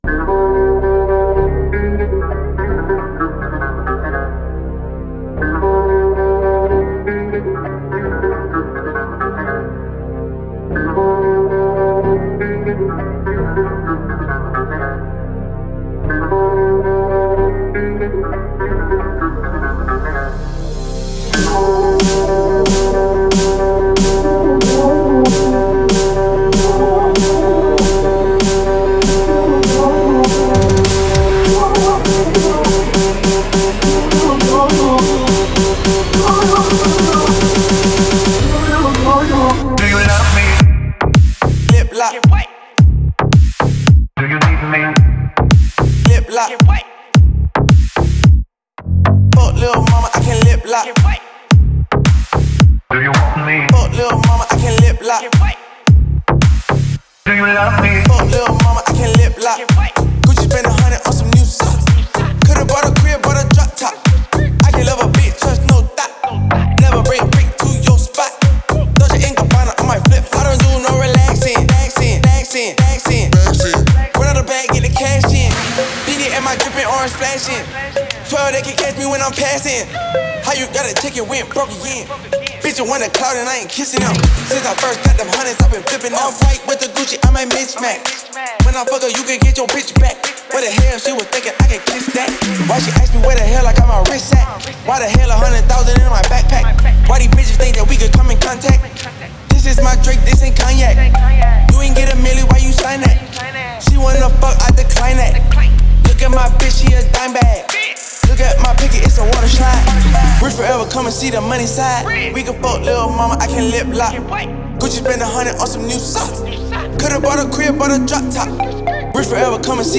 энергичный трек в жанре EDM